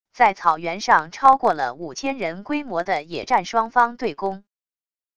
在草原上超过了五千人规模的野战双方对攻wav音频